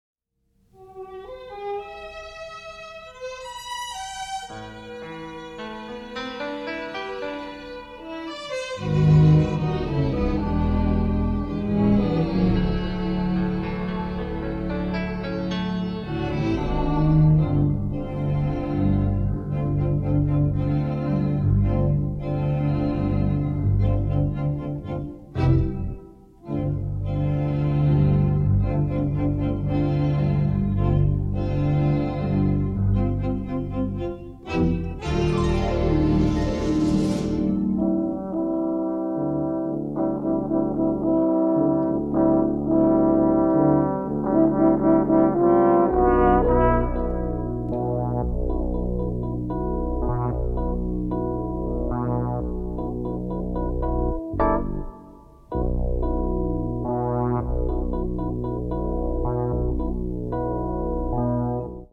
combines a full symphony orchestra with custom synthesizers